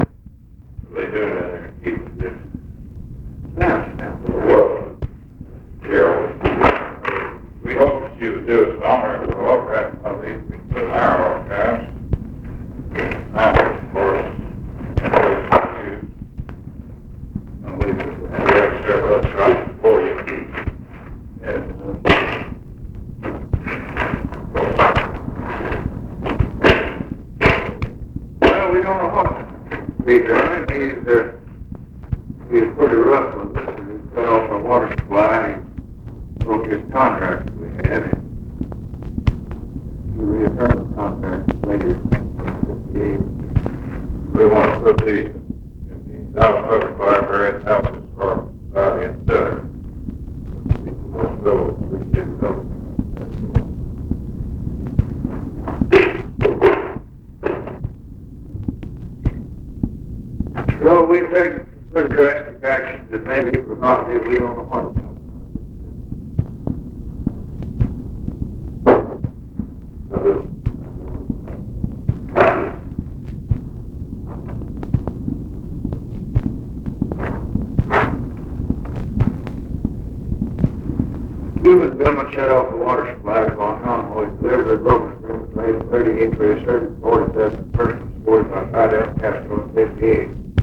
OFFICE CONVERSATION, February 7, 1964
Secret White House Tapes | Lyndon B. Johnson Presidency